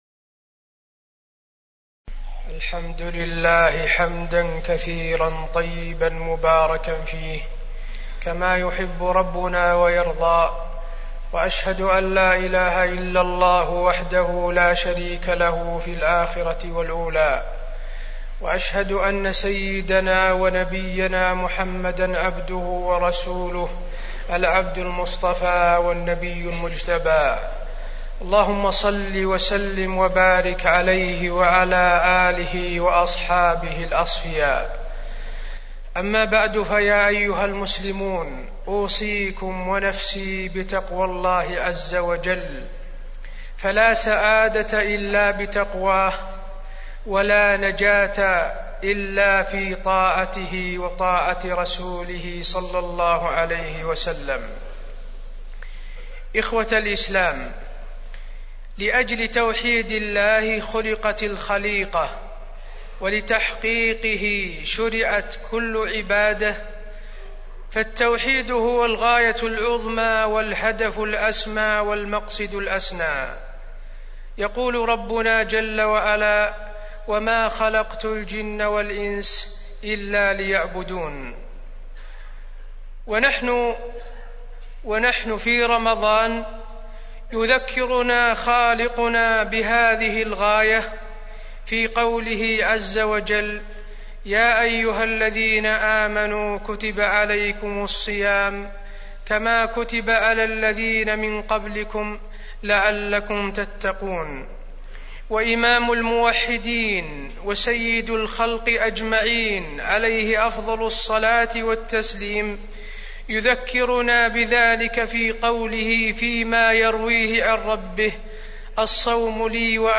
تاريخ النشر ١١ رمضان ١٤٢٦ هـ المكان: المسجد النبوي الشيخ: فضيلة الشيخ د. حسين بن عبدالعزيز آل الشيخ فضيلة الشيخ د. حسين بن عبدالعزيز آل الشيخ النهي عن تعظيم القبور والغلو في الصالحين